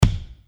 bassdrum (x99)
kick.mp3